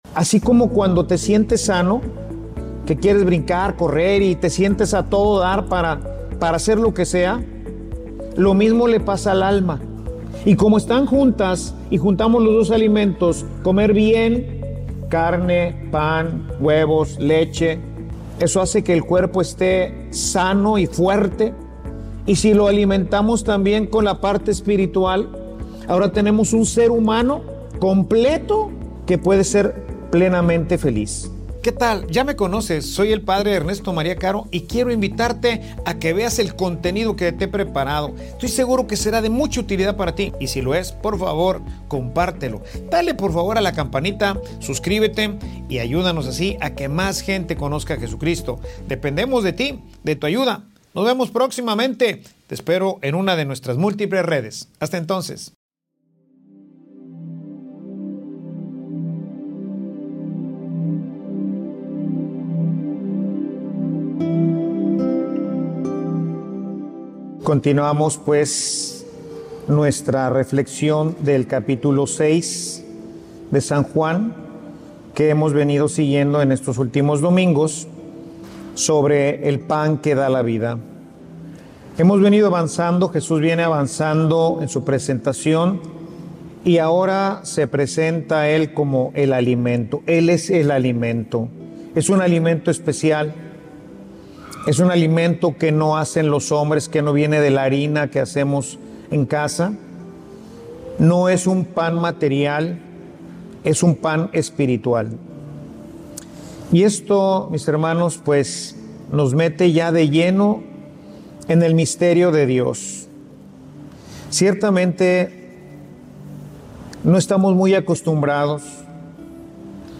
Homilia_Alimentate_correctamente.mp3